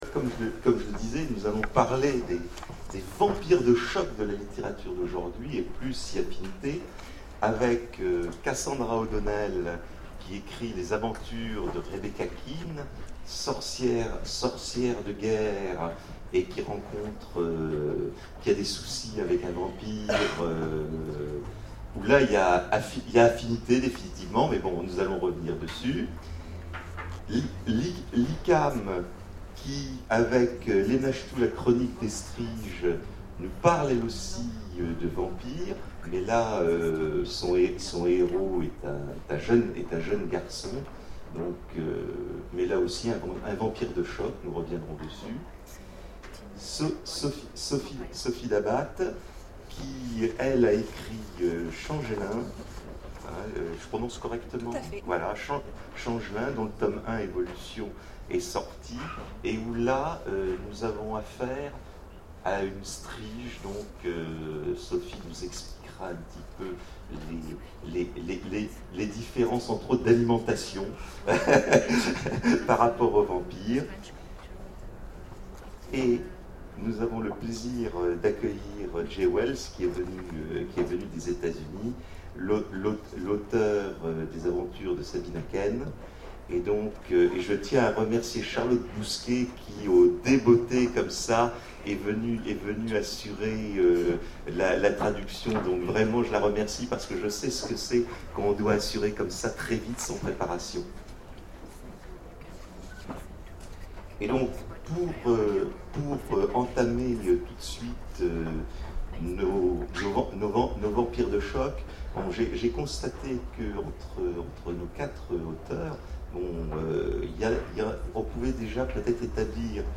Imaginales 2012 : Conférence Vampires de choc